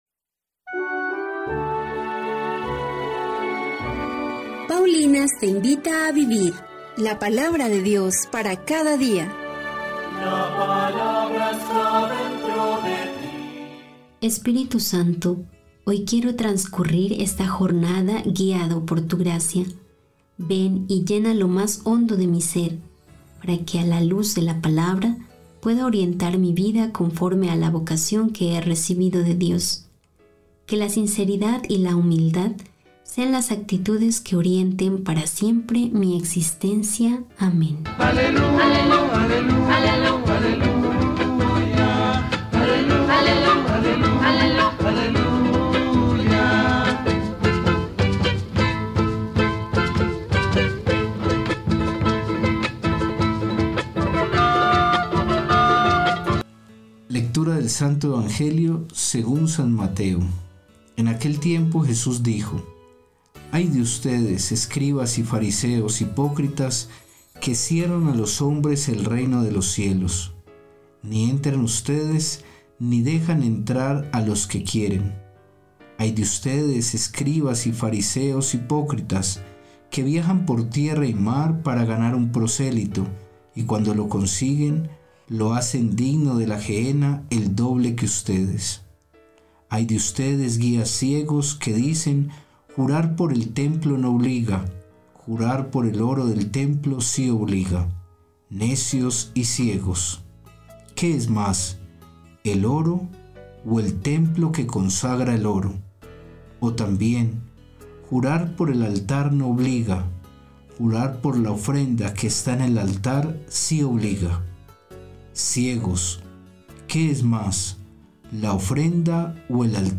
Lectura del libro de Rut 2, 1-3. 8-11; 4, 13-17